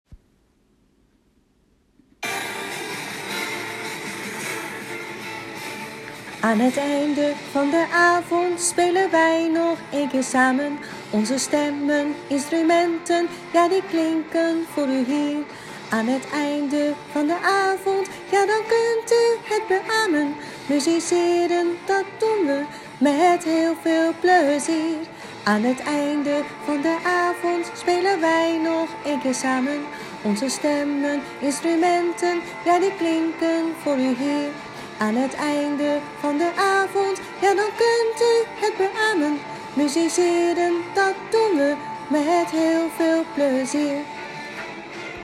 voorbeeld-van-zangpartij.mp3